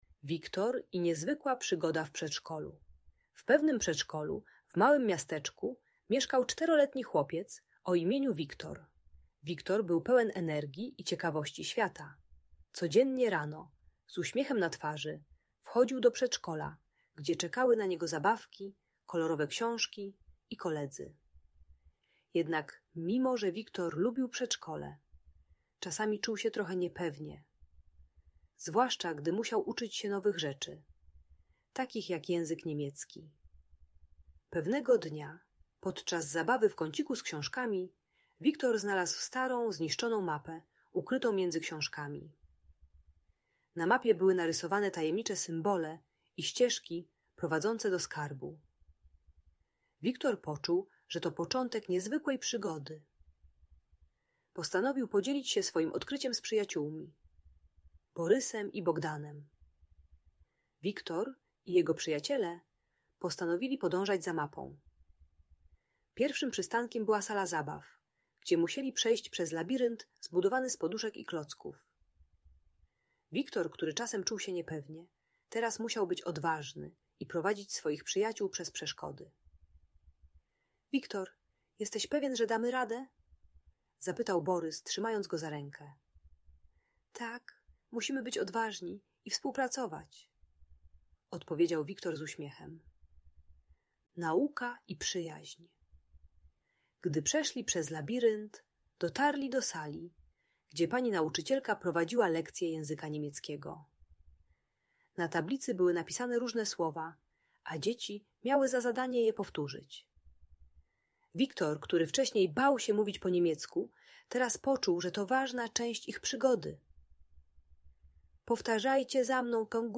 Przygody Wiktora w przedszkolu - Bajki Elektronika | Audiobajka